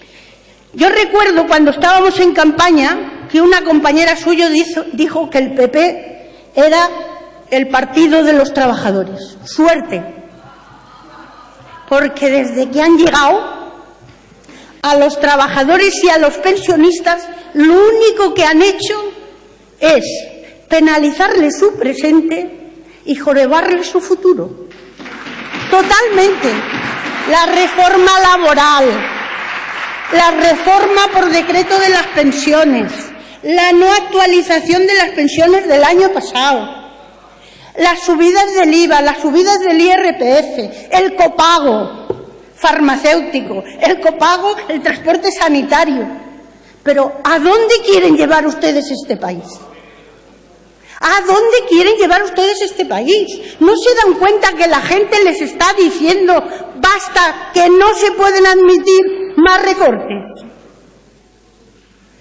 Pleno del Congreso. Isabel López i Chamosa. Debate sobre la convalidación del real decreto ley para la reforma de la jubilación